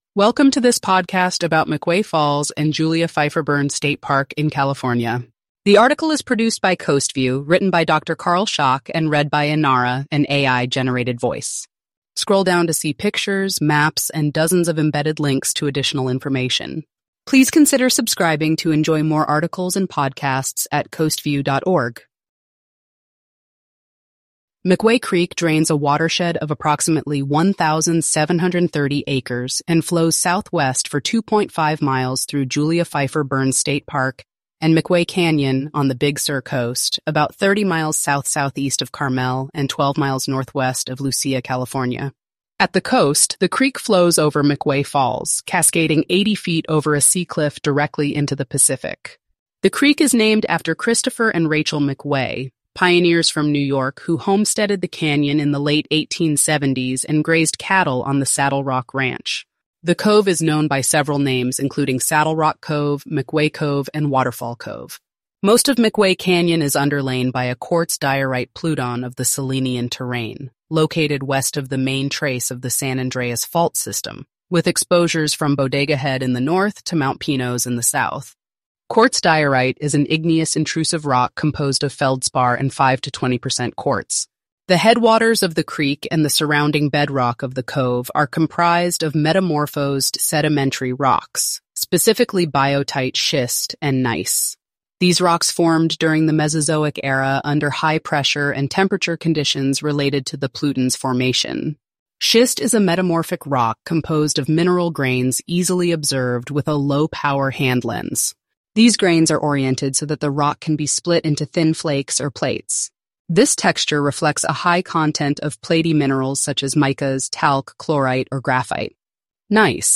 McWay-Falls.mp3